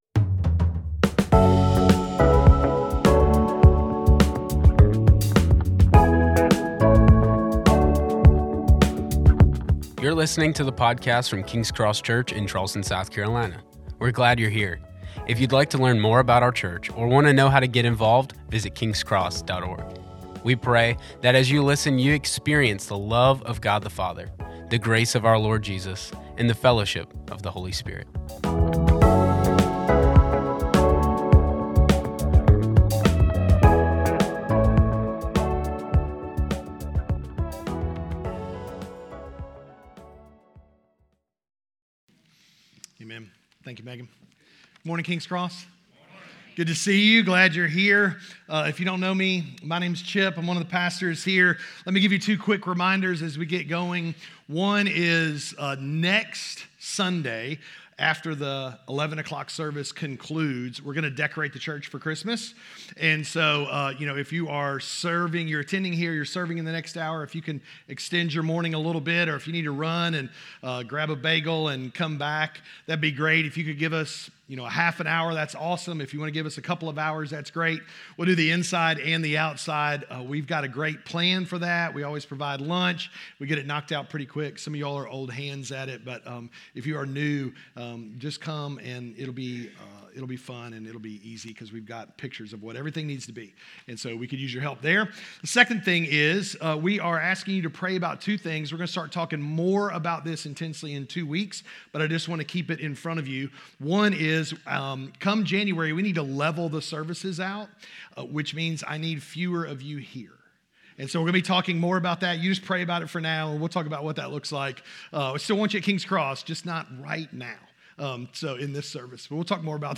A message from the series "...ology." What impact does the promised return of Christ have on your life, today?